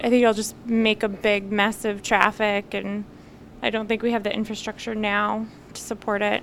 THIS WOMAN ARGUES HOSTING THE 2024 SUMMER GAMES IN BOSTON WILL MAKE A BIG MESS OF TRAFFIC AND THAT THERE IS NO INFRASTRUCTURE NOW TO SUPPORT IT.